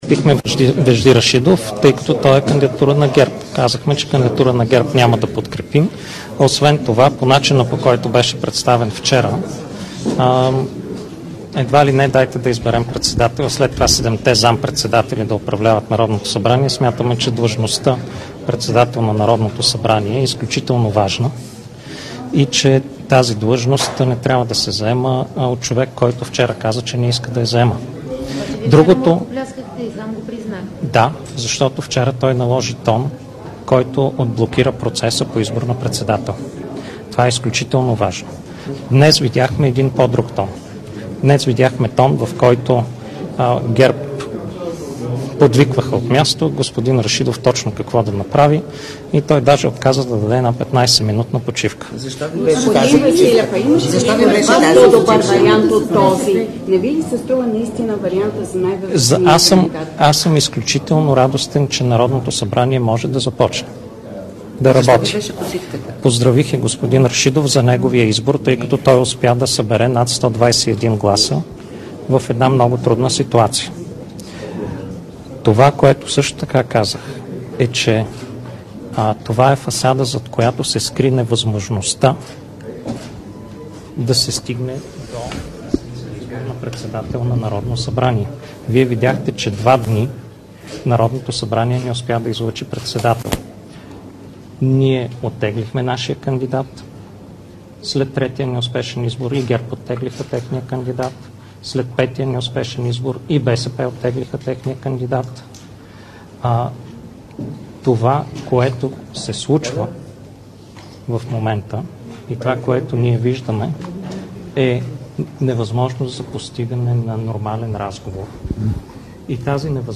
Briefing Asen Vasilev 14 25H 21 10 22